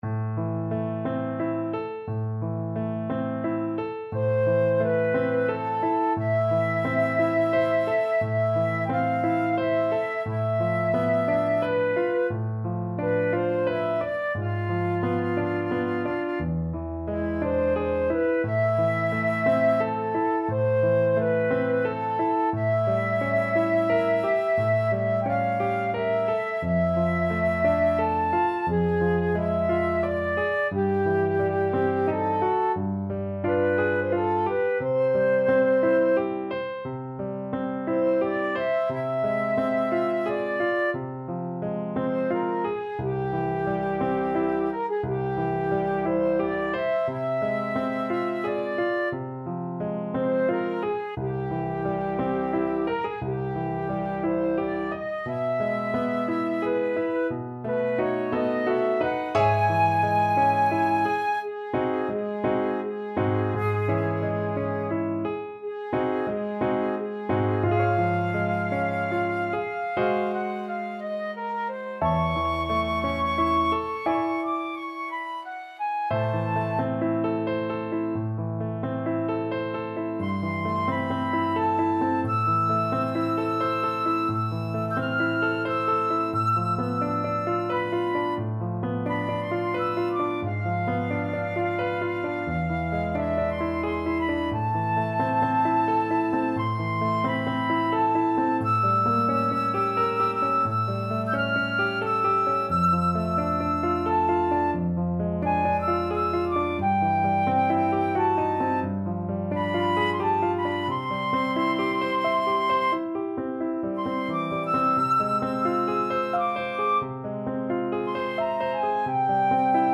Flute
~ = 88 Malinconico espressivo
A minor (Sounding Pitch) (View more A minor Music for Flute )
3/4 (View more 3/4 Music)
Classical (View more Classical Flute Music)